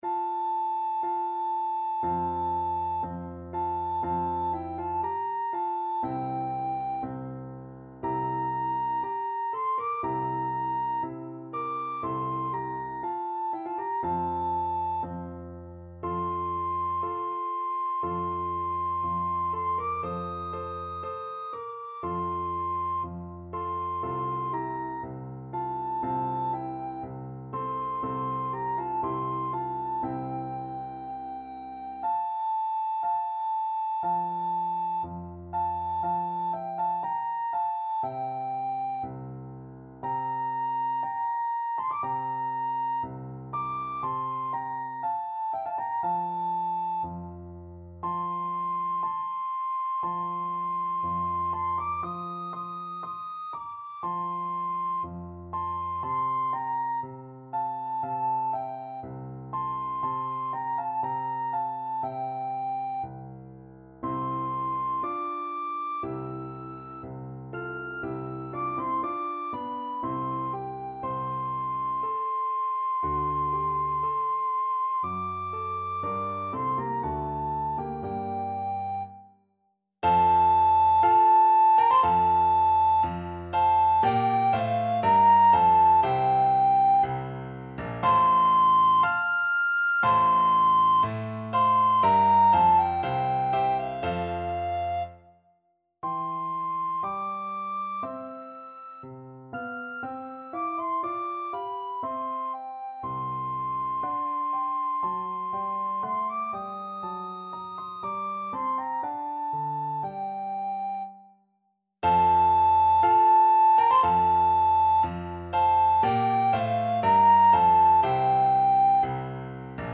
Classical Handel, George Frideric Funeral March from Saul Soprano (Descant) Recorder version
F major (Sounding Pitch) (View more F major Music for Recorder )
Slow =c.60
4/4 (View more 4/4 Music)
Classical (View more Classical Recorder Music)